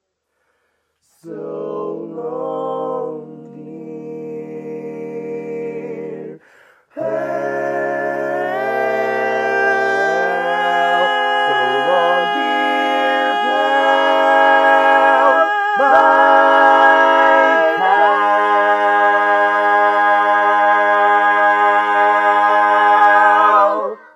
Key written in: A♭ Major
How many parts: 4
Type: Barbershop
All Parts mix:
Learning tracks sung by